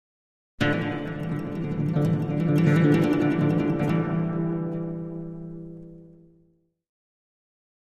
Acoustic Guitar - Guitars Long Legato 2